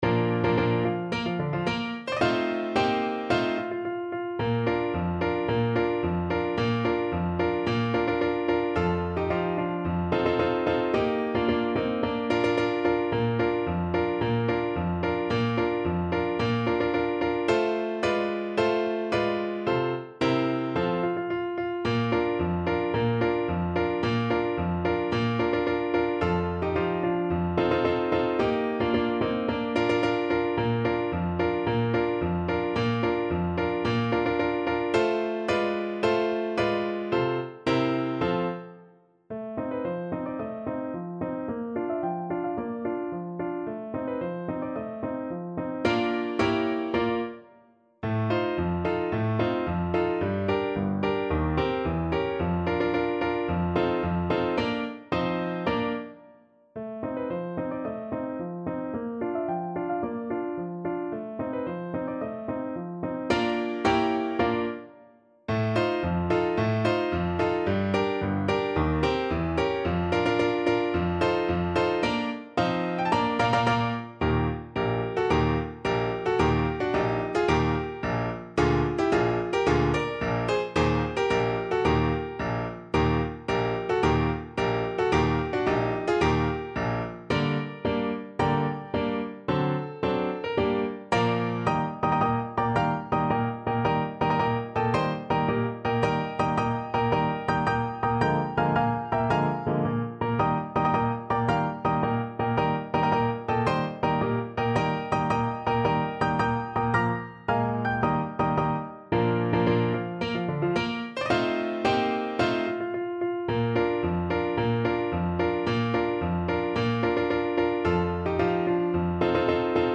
2/2 (View more 2/2 Music)
March =c.110